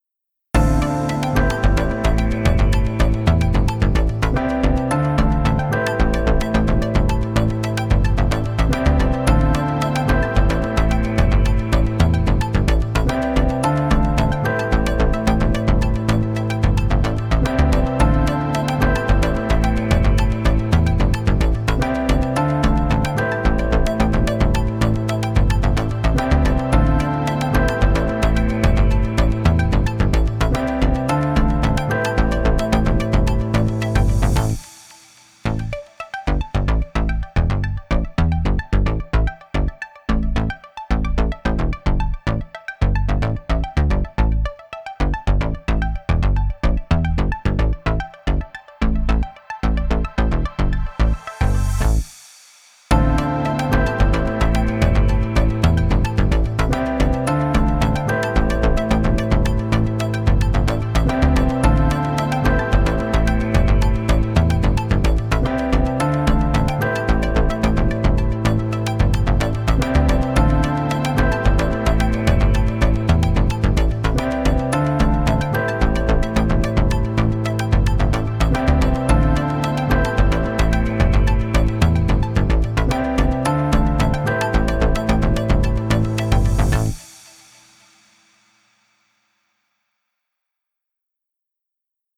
【ループ＆バリエーション＆耐久版あり】探偵や推理、謎解きの雰囲気があるBGMです
かっこいい/おしゃれ/ダーク/不気味/サスペンス/ゲーム/アニメ
▼ドラムなし版